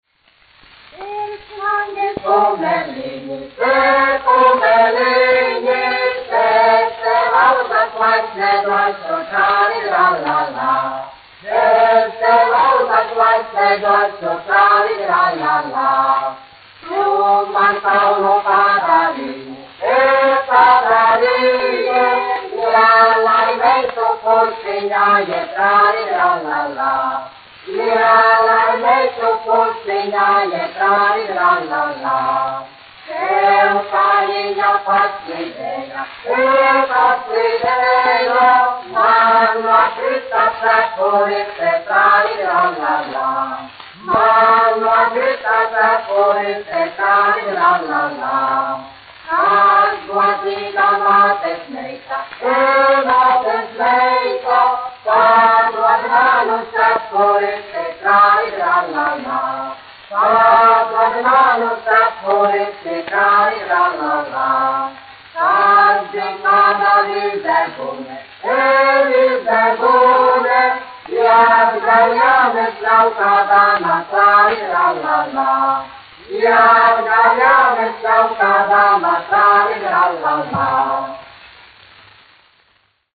Alsungas novada dziedoņi, izpildītājs
1 skpl. : analogs, 78 apgr/min, mono ; 25 cm
Latviešu tautasdziesmas
Skaņuplate